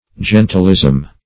Search Result for " gentilism" : The Collaborative International Dictionary of English v.0.48: Gentilism \Gen"til*ism\, n. [Cf. F. gentilisme.]